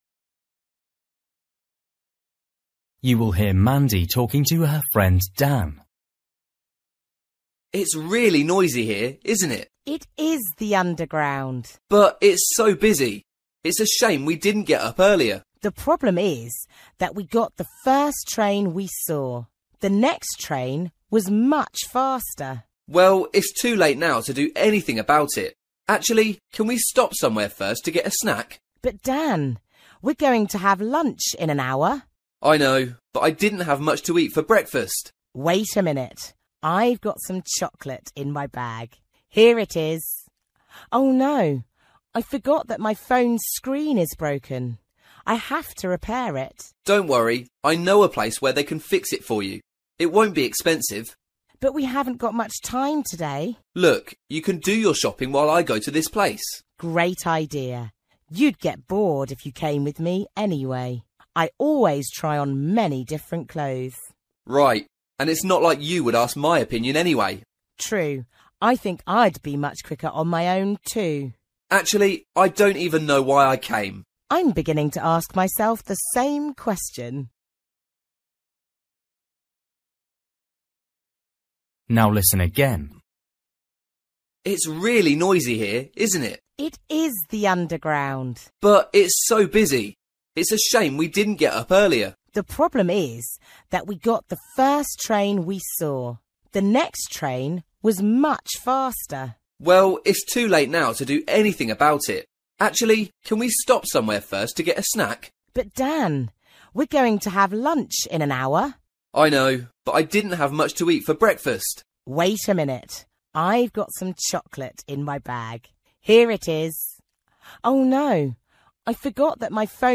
Bài tập trắc nghiệm luyện nghe tiếng Anh trình độ sơ trung cấp – Nghe một cuộc trò chuyện dài phần 22